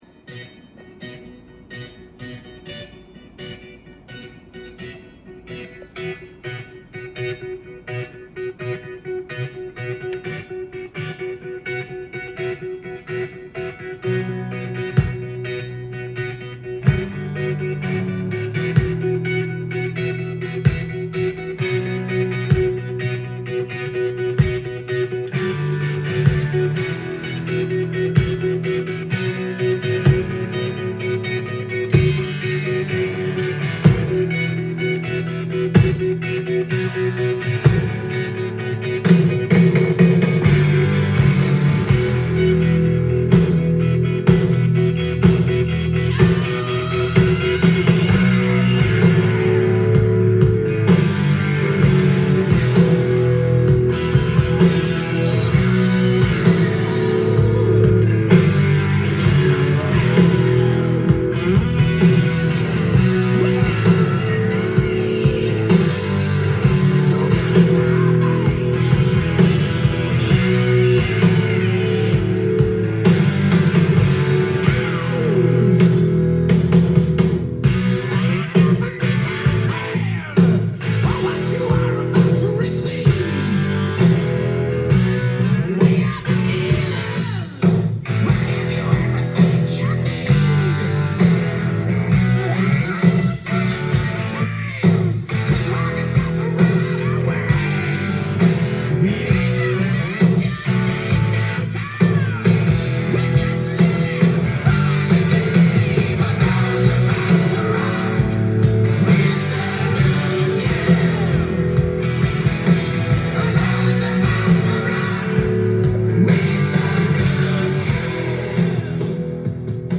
ジャンル：HARD-ROCK
店頭で録音した音源の為、多少の外部音や音質の悪さはございますが、サンプルとしてご視聴ください。